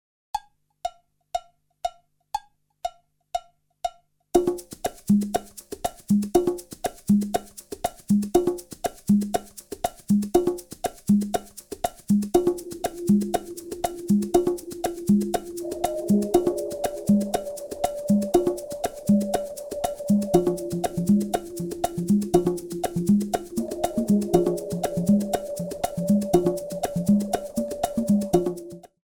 15 tracks of original percussion music
Nice consistent long tracks  - world music style